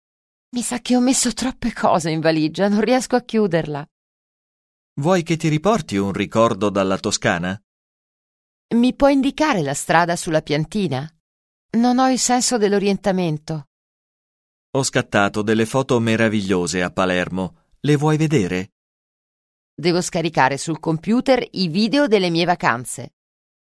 Un peu de conversation - Préparer son voyage